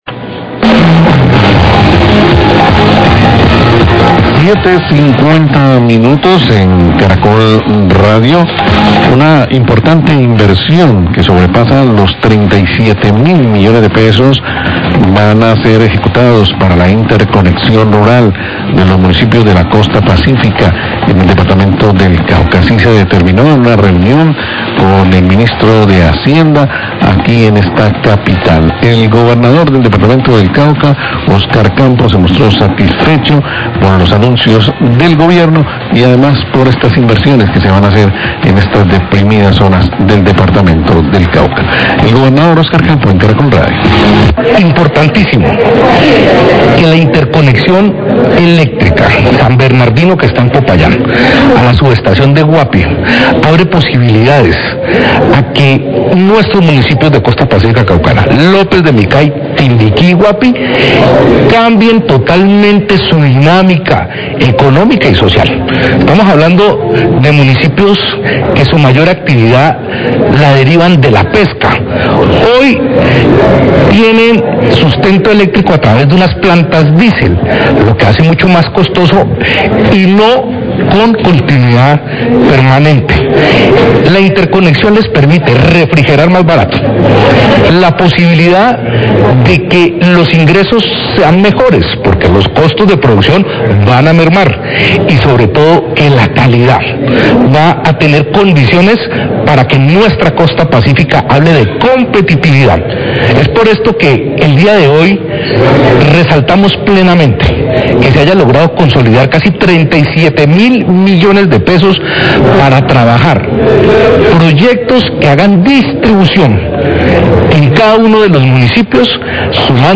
Radio
Importante inversión a través del Plan Todos Somos Pazcífico, va a ser ejecutada en la interconexión eléctrica de los municipios de la costa pacífica caucana, La interconexión eléctrica va desde San Bernardino, Popayán a la subestación de Guapi. Declaraciones del Gobernador del Cauca, Oscar Campo.